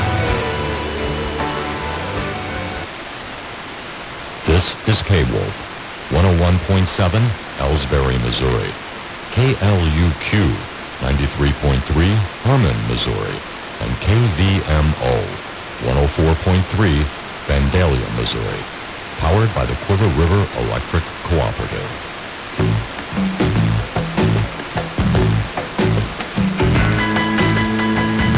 In addition to the stations shown in the local dial guide these were received this morning at about 5:40 AM: